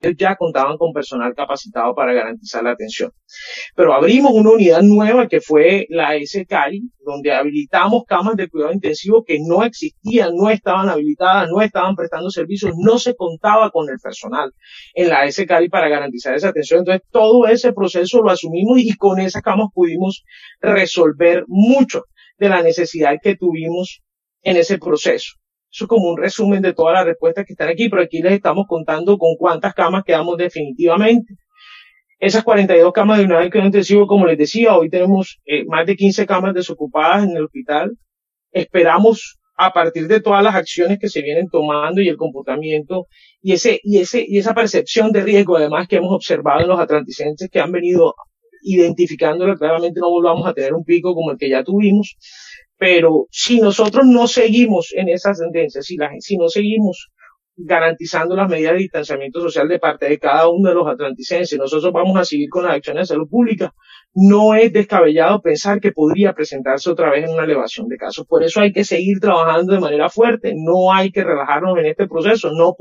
En este sentido se cumplió la sesión virtual de la Duma en la que se hizo el debate de control político a la salud, Se precisó que las inversiones en equipos científicos y obras civiles superan los 4 mil 173 millones de pesos, además se indició que en julio aumentó el número de muestras tomadas en el laboratorio departamental y en la actualidad se procesan mas de 613 pruebas diarias.
VOZ-ALMA-SOLANO-EN-LA-ASAMBLEA.mp3